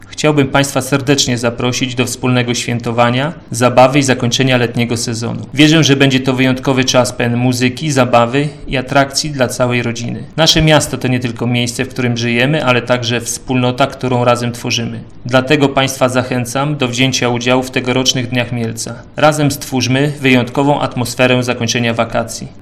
Mówi prezydent Mielca, Jacek Wiśniewski.